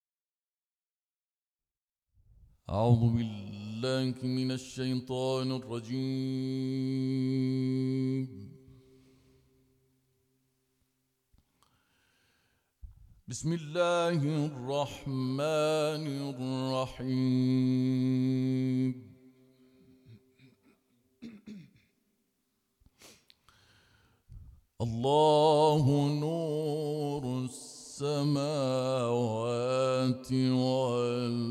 所有标签: 古兰经 ، 诵读 ، 伊朗